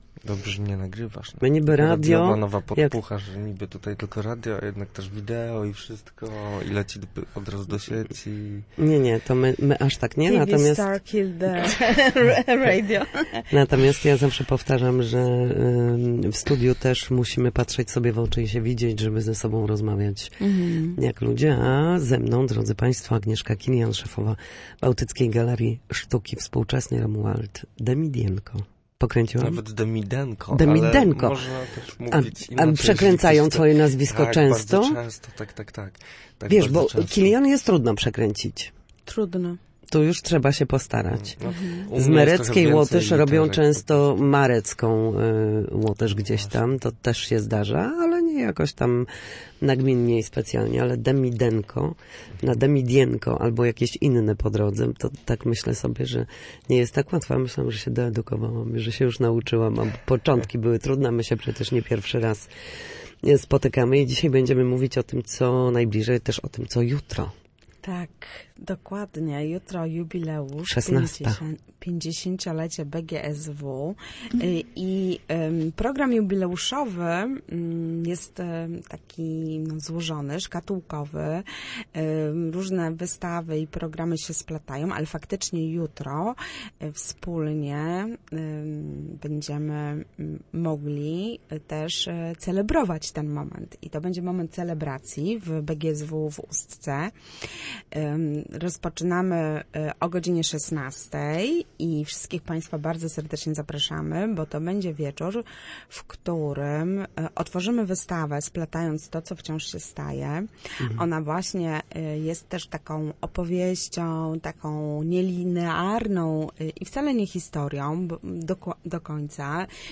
Na naszej antenie zapraszali na najbliższe wystawy i działania związane z jubileuszem.